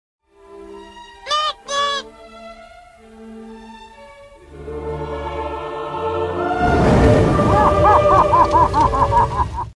Pyro does a Noot Noot sound effects free download